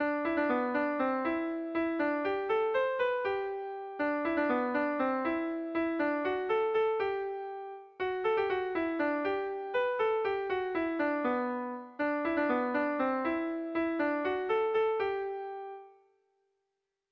Melodías de bertsos - Ver ficha   Más información sobre esta sección
Zortziko txikia (hg) / Lau puntuko txikia (ip)
A1A2BA2